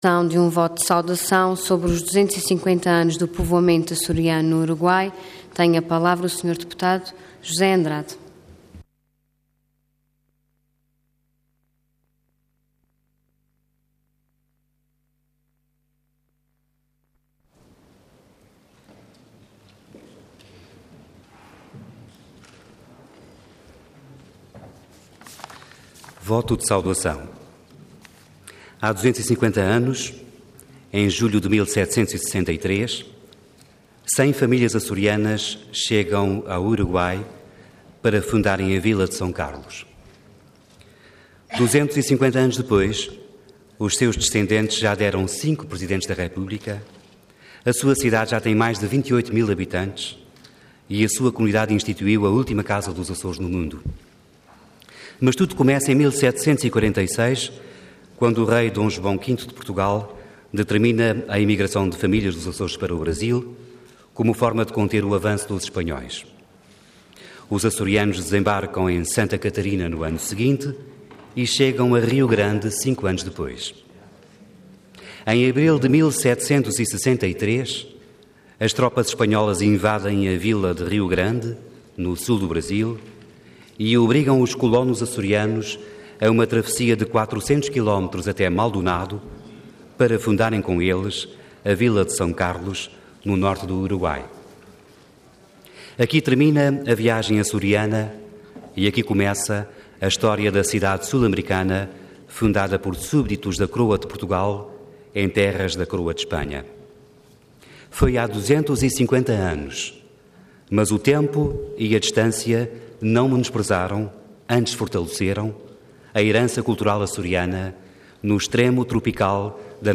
Intervenção Voto de Saudação Orador José Andrade Cargo Deputado Entidade PSD